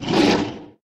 TBs Angry Chimera Growls
tb_lurk_4.ogg